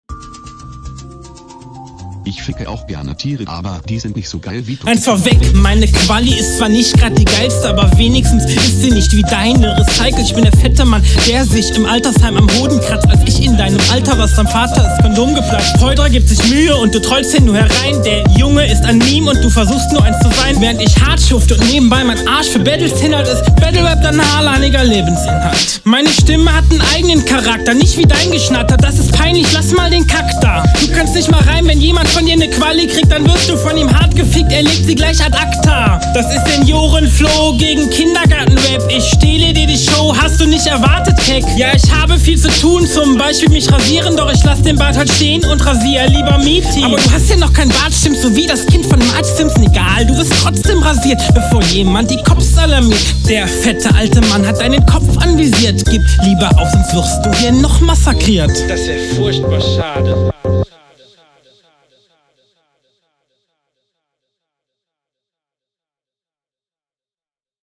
Flow: Finde den Stimmeinsatz und die Delivery hier sehr gewönungbedürftig, aber die Silbensetztung und die …
Flow: Sehr eigene stimme auf jeden fall und auch der stimmeinsatz ist sehr erfrischend! Du …